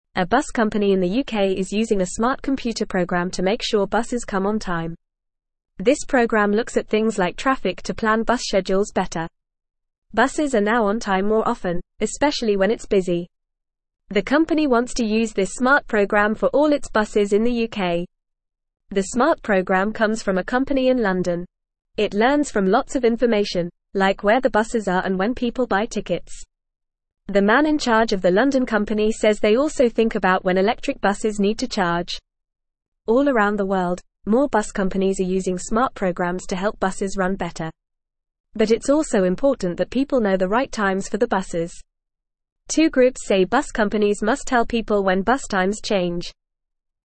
Fast
English-Newsroom-Beginner-FAST-Reading-Smart-Program-Helps-UK-Buses-Arrive-on-Time.mp3